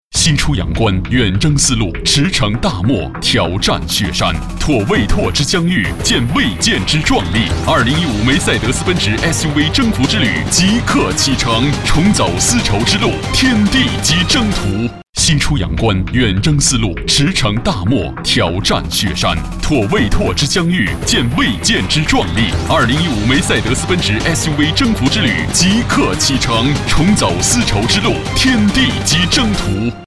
职业配音员全职配音员央视纪录片配音员
• 男11 国语 男声 广告-奔驰汽车广告奔驰SUV-B版本-01 大气浑厚磁性|沉稳|积极向上|时尚活力